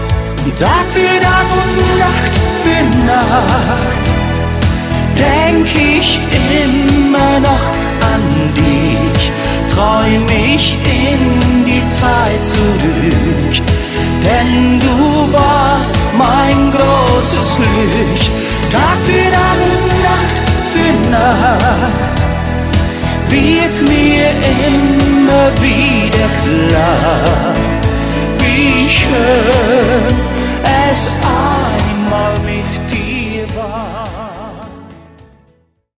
Die Schlagerband mit Herz für jeden Anlass